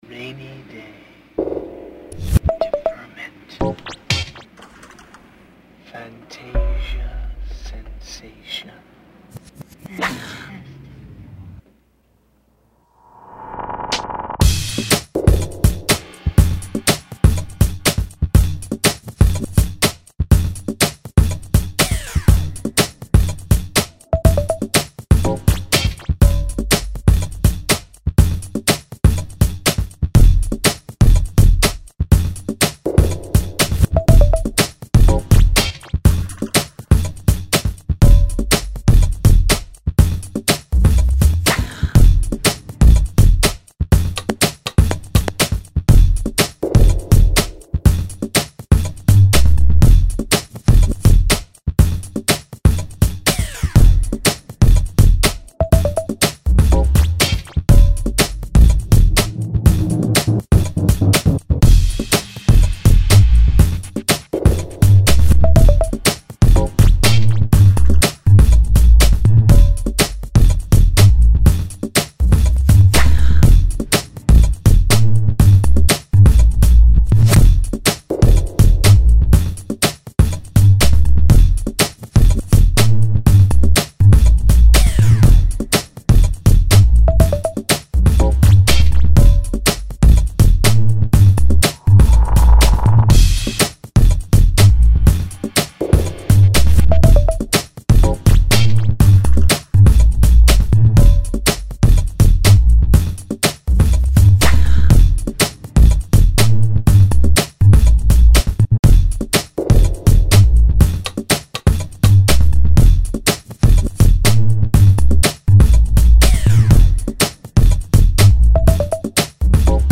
Deep dark techno